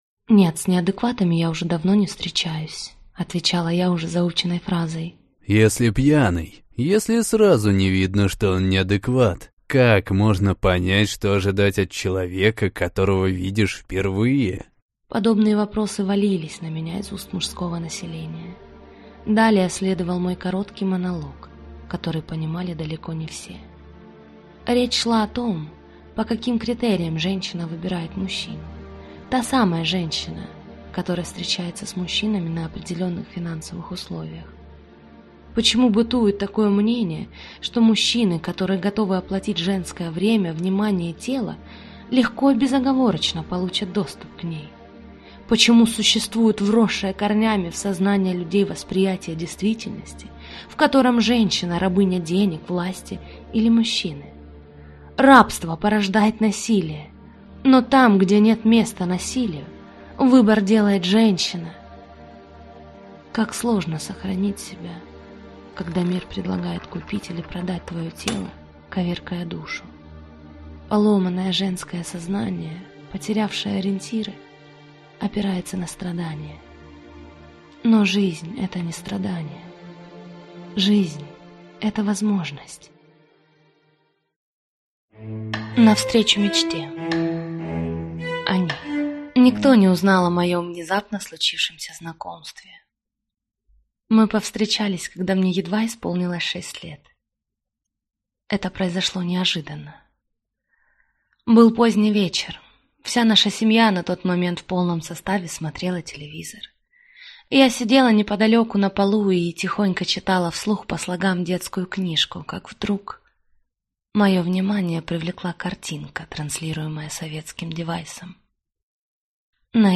Аудиокнига Женщина-Волшебство | Библиотека аудиокниг
Прослушать и бесплатно скачать фрагмент аудиокниги